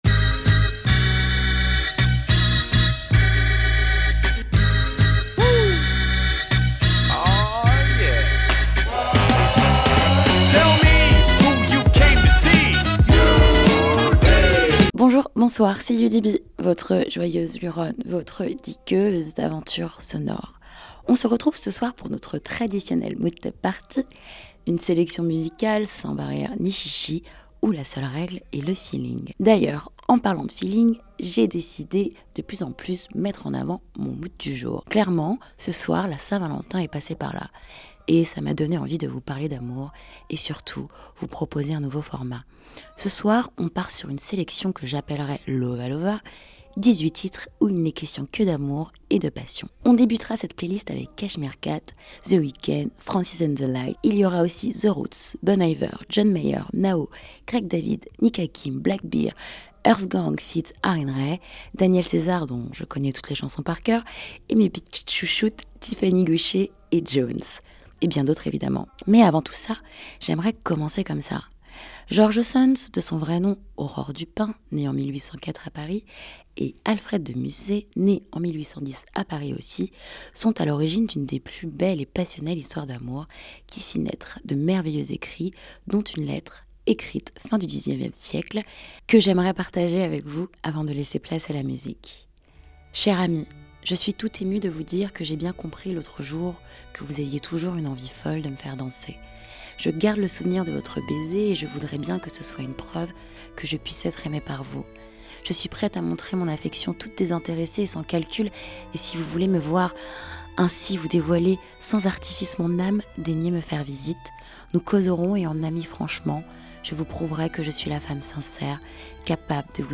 Une programmation musicale, de 00h à 01h tous les mardis soir, sur vos ondes.